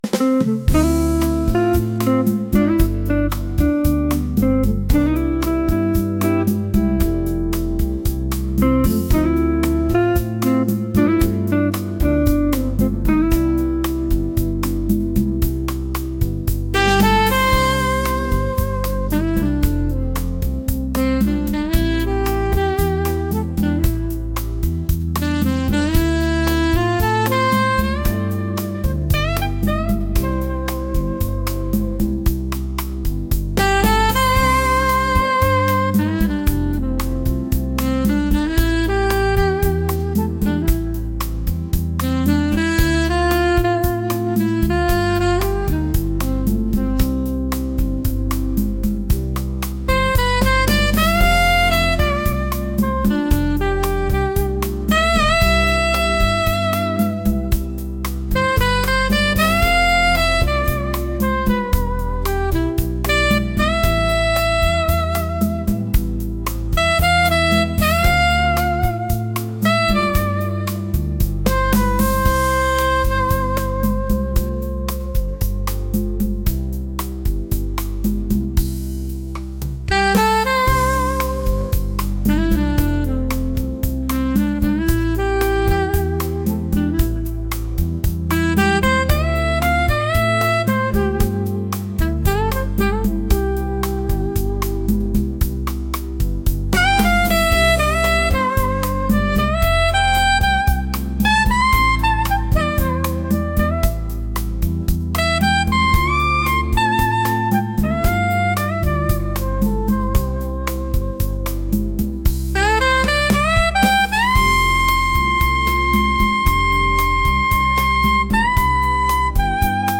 smooth | jazz